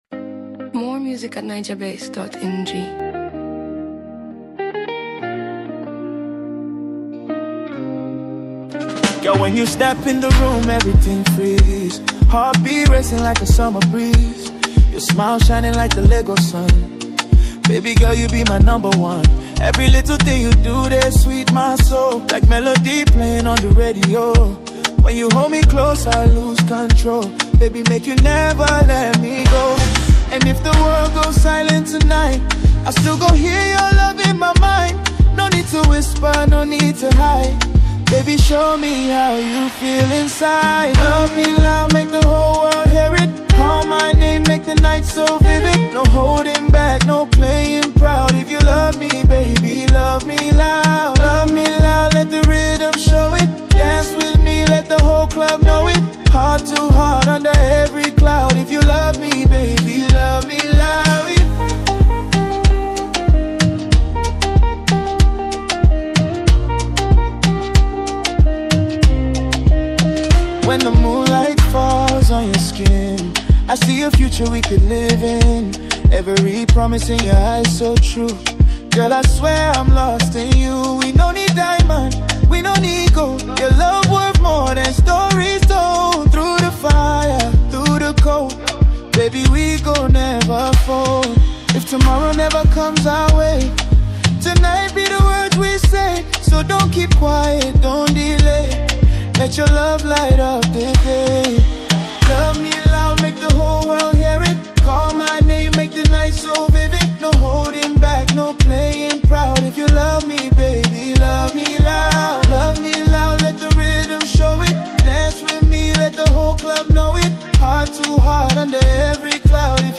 romantic and sensual love song
🔥 Short vibe summary Romantic ❤ Sweet but also sensual 😏